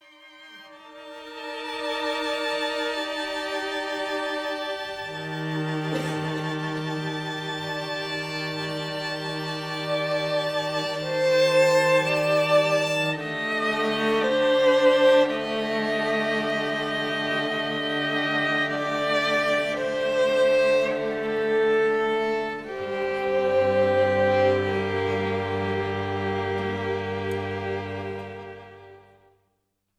A selection of beautifully arranged music for Shabbat